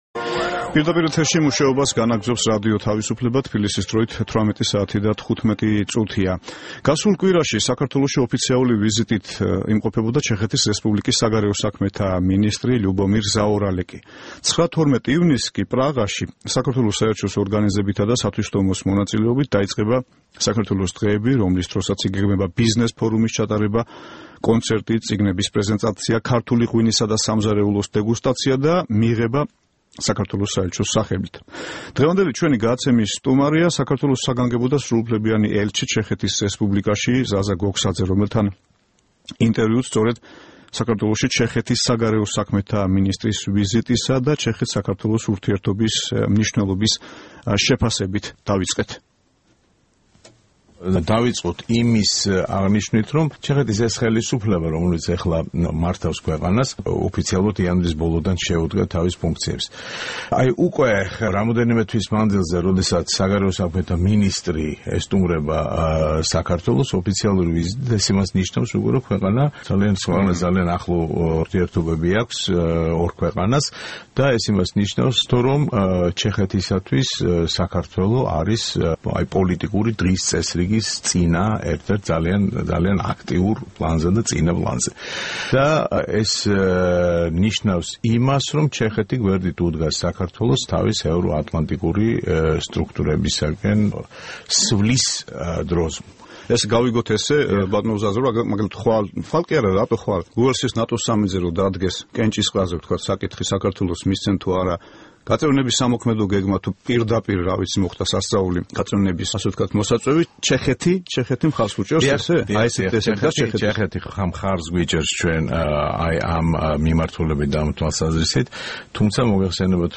5 ივნისს საქართველოში ოფიციალური ვიზიტით იმყოფებოდა ჩეხეთის რესპუბლიკის საგარეო საქმეთა მინისტრი ლიუბომირ ზაორალეკი. 9-12 ივნისს პრაღაში საქართველოს საელჩოს ორგანიზებით იწყება საქართველოს დღეები. ჩვენი სტუმარია საქართველოს საგანგებო და სრულუფლებიანი ელჩი ჩეხეთის რესპუბლიკაში, ზაალ გოგსაძე.